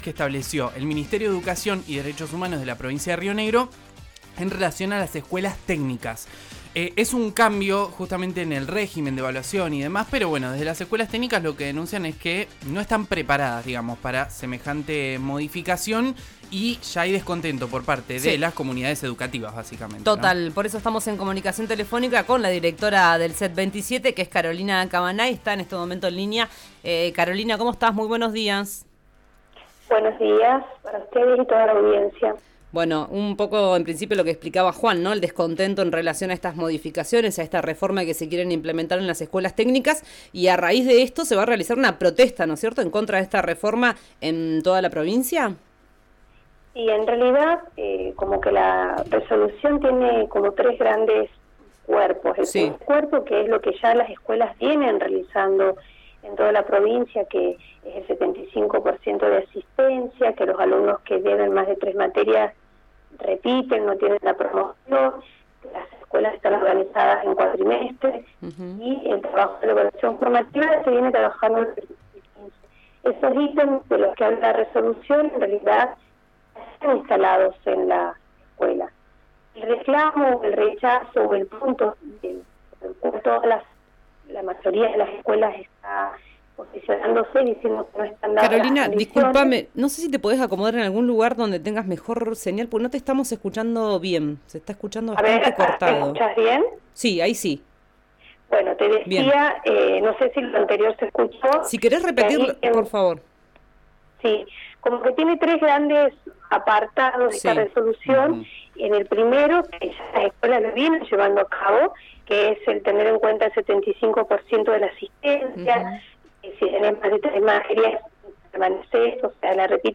en diálogo con RÍO NEGRO RADIO.